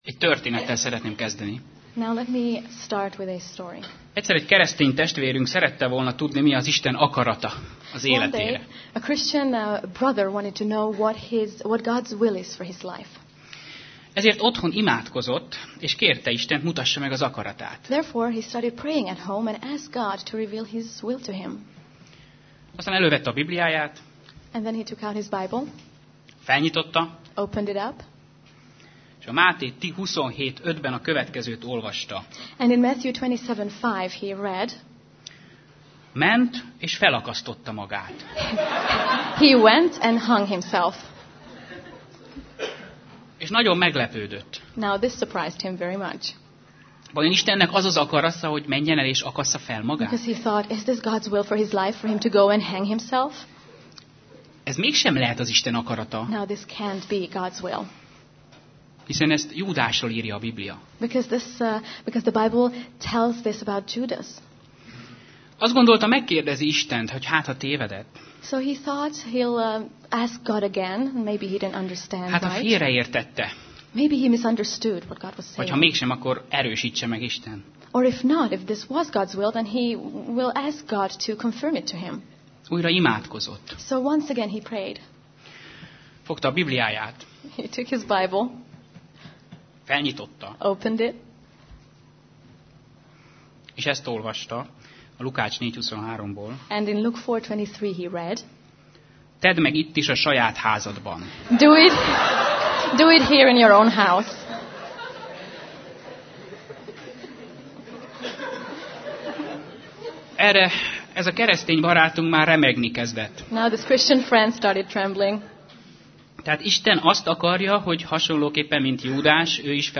Sorozat: Vajta Konferencia 2005 Alkalom: Konferencia